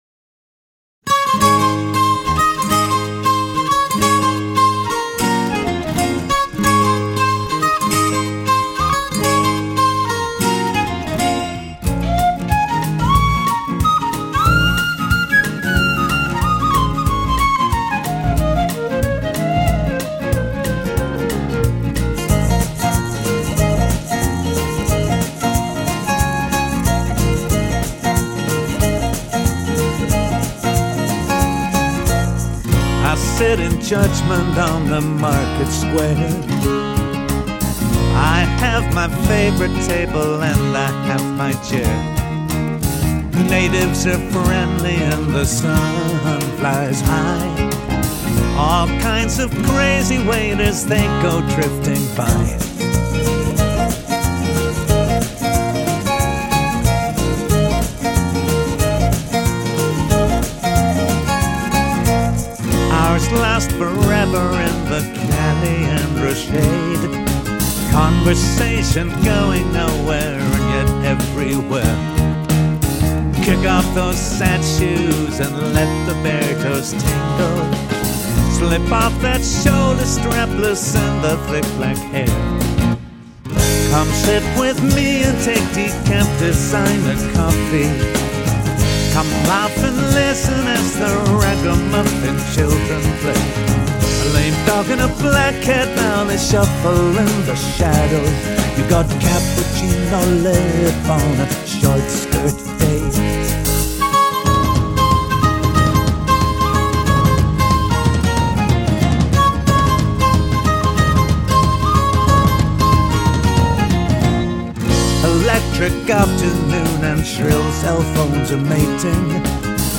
laid-back, more acoustic work